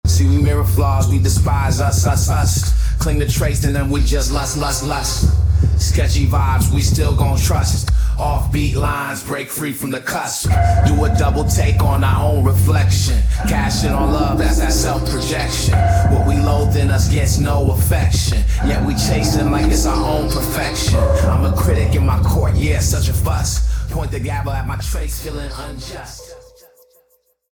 An incredible narrative Hip Hop song, creative and visual.